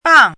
怎么读
bàng
bang4.mp3